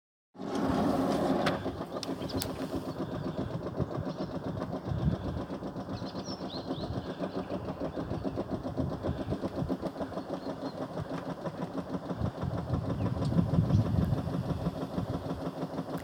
C'est la fin de la montée qui est suivi de ce fameux "tac tac" comme je l'appelle.
Cela peut durer plusieurs secondes, même une à 2 minutes).
Somfy montée volet.mp3 Ecouter Somfy montée volet.mp3
somfy_mont_e_volet_original.mp3